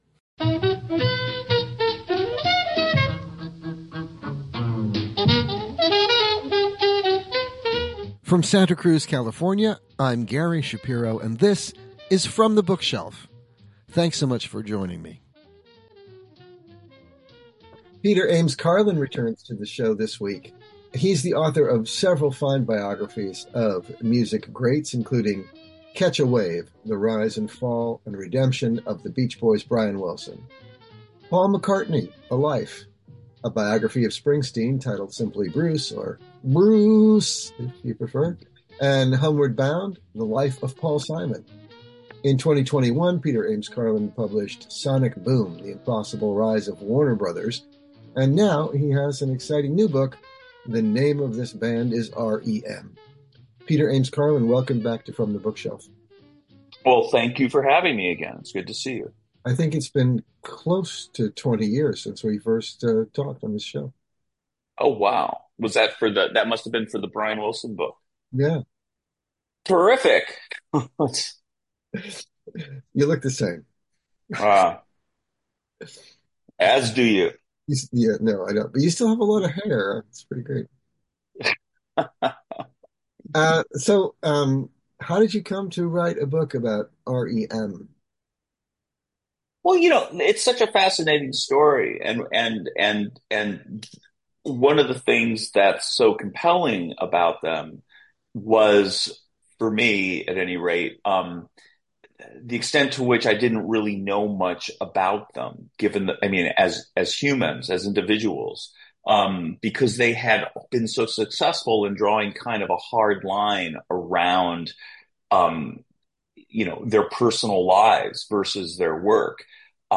From the Bookshelf is heard on radio station KSQD in Santa Cruz California.